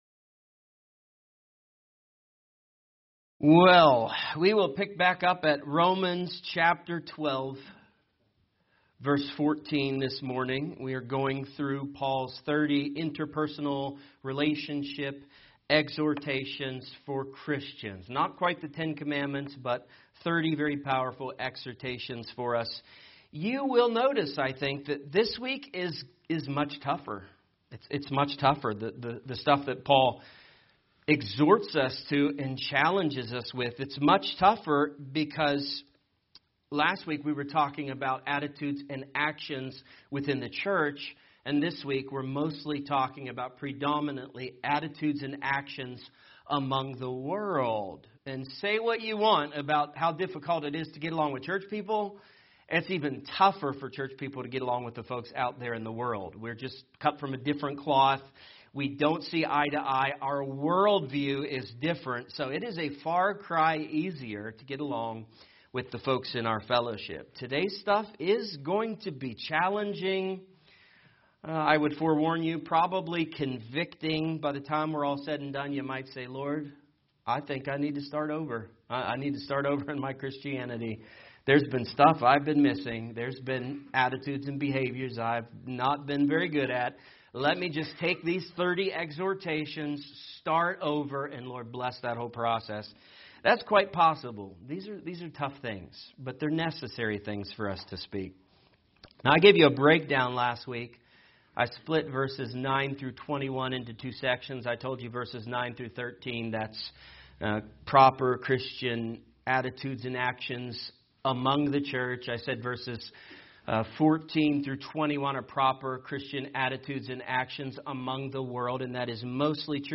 A message from the topics "The Book of Romans."